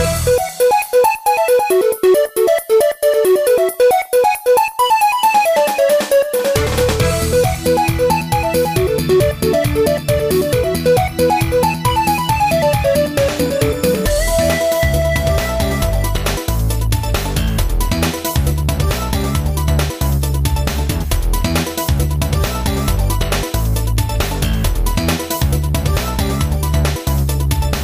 • Качество: 128, Stereo
электронная музыка